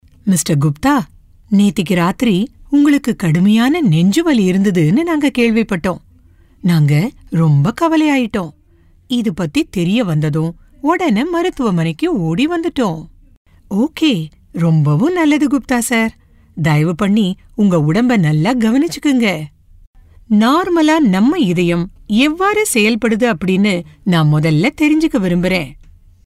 Tamil Voice Over Artists Samples for Tamil AI Voice
Tamil Voice Over Female Artist 1